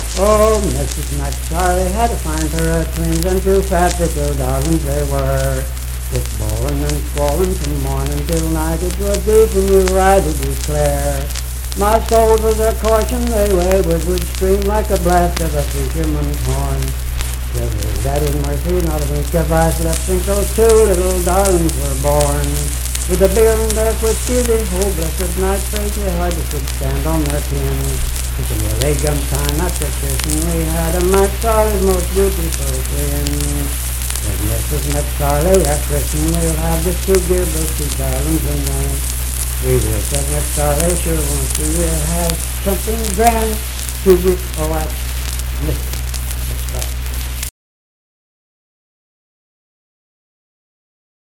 Unaccompanied vocal performance
Verse-refrain 1d (8).
Voice (sung)
Harrison County (W. Va.)